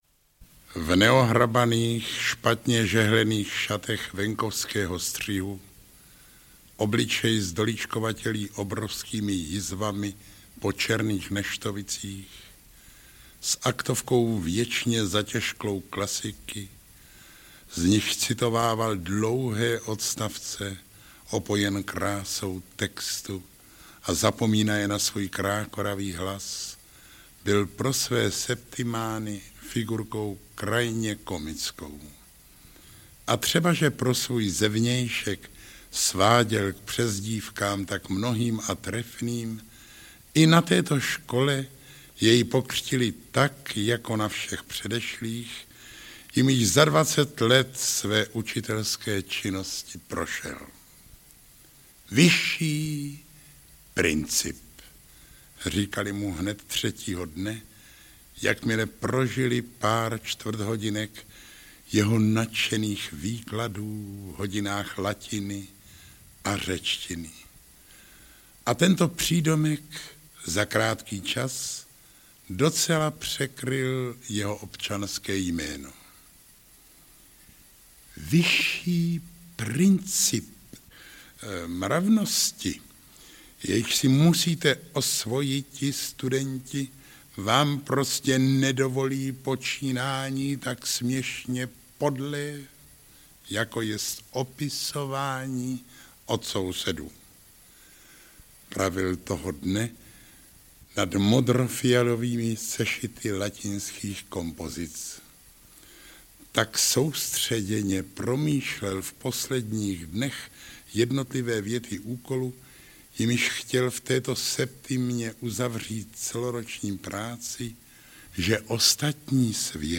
Audiobook
Read: Zdeněk Štěpánek